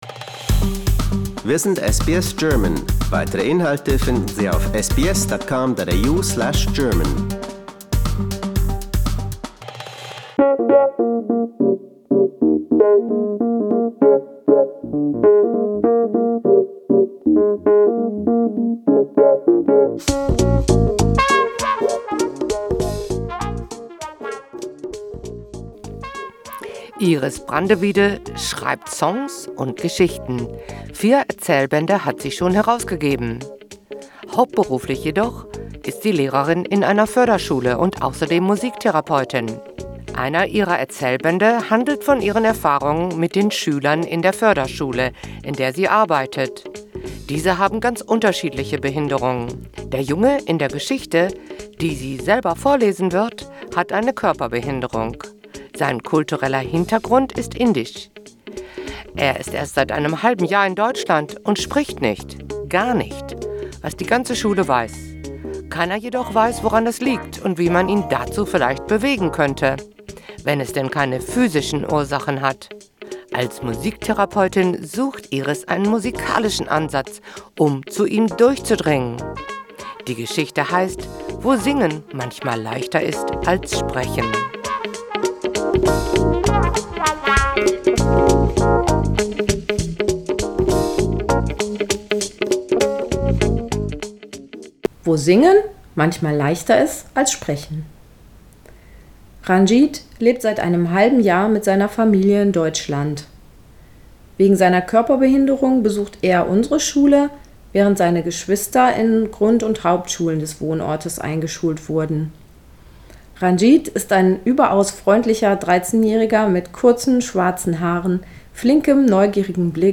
Autorenlesung: Das Fleischklöschenschauspiel